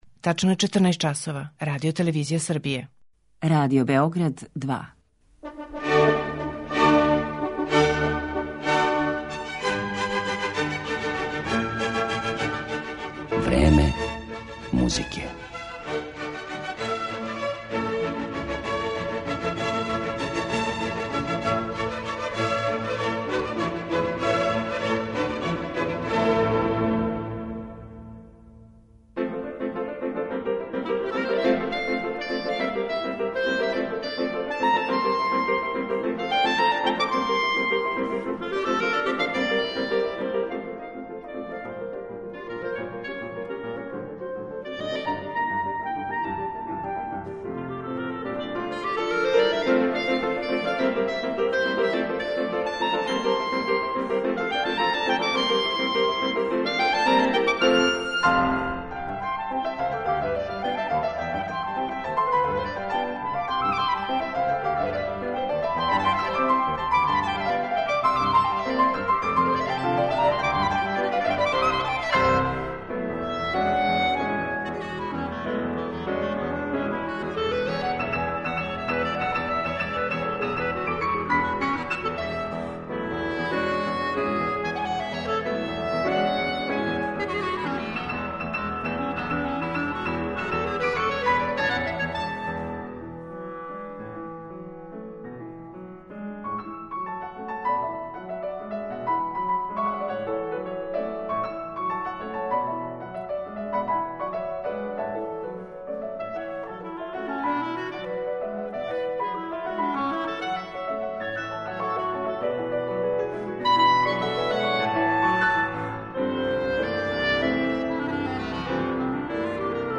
Још од тада, Колинс свира кларинет са неким потпуно магичним састојком. Критичари хвале његову врхунску вештину, савршену музикалност, огроман спектар боја, осећај за мелодију - независно да ли свира као солиста, камерни или оркестарски музичар.